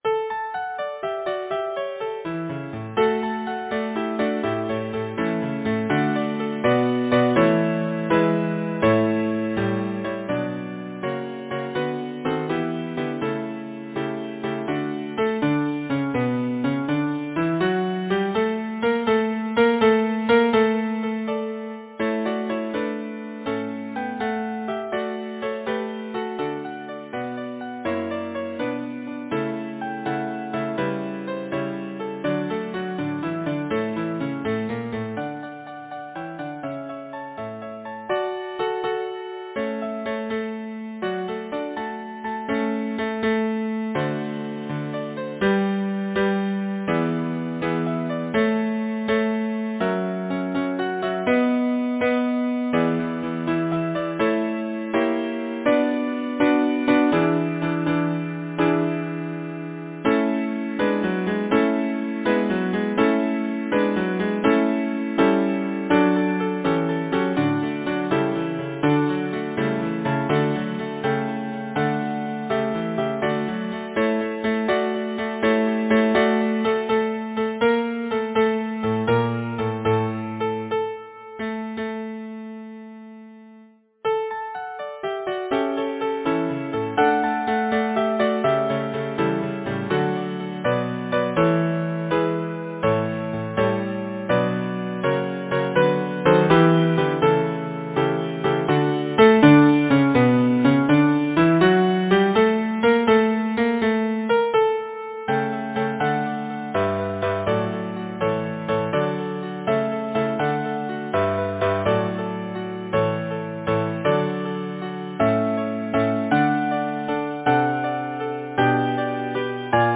Title: Little Jack Horner Composer: Albert Ham Lyricist: Number of voices: 4vv Voicing: SATB Genre: Secular, Partsong, Nursery rhyme, Humorous song
Language: English Instruments: Piano